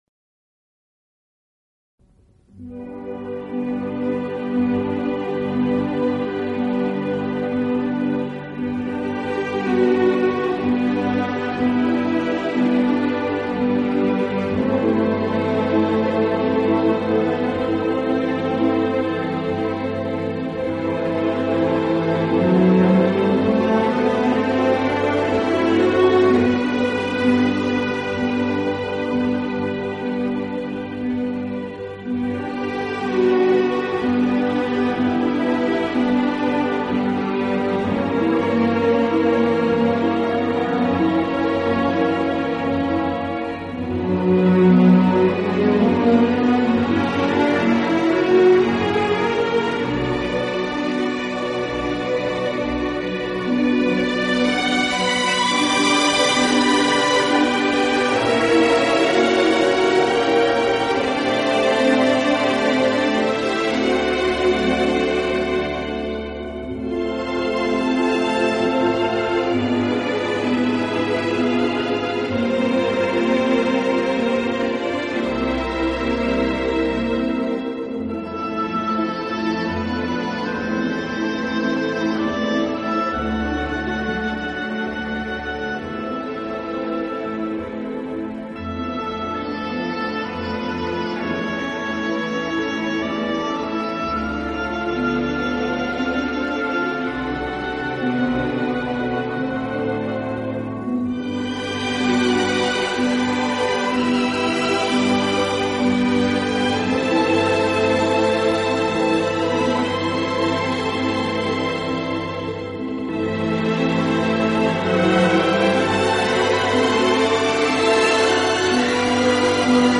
乐队具有完整的管乐器组和弦乐器